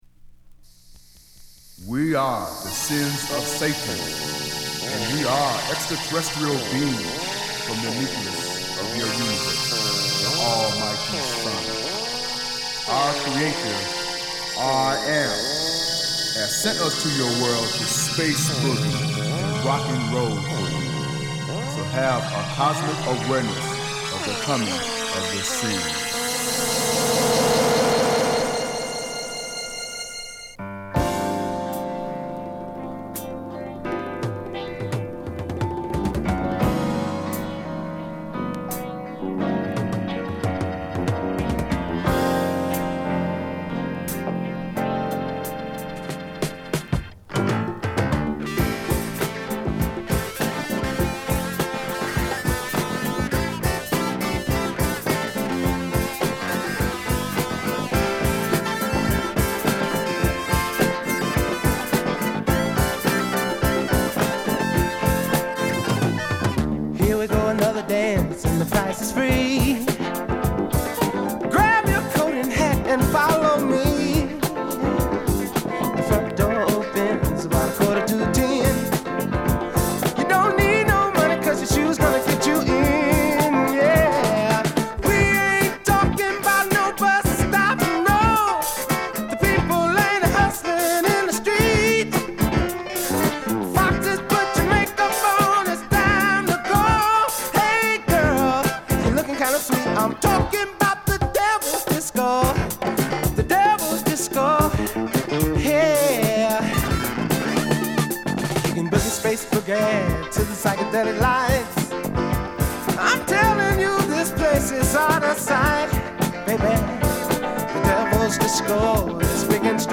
ジャケットから怪しい雰囲気が漂う、デトロイト発のオブスキュア・ソウル名作！